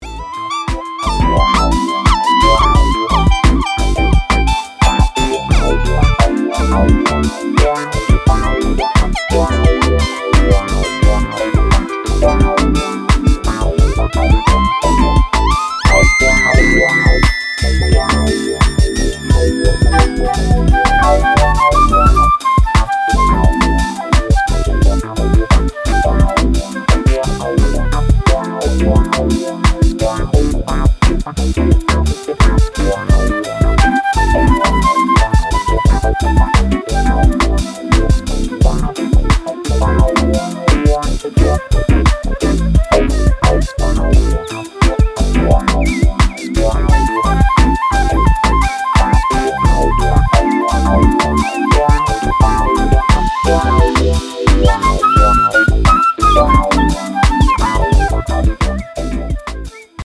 生演奏をたっぷりと用いながら豊かな音楽性を湛えたモダンなジャズ/フュージョン・ハウス〜ブレイクビーツを繰り広げています。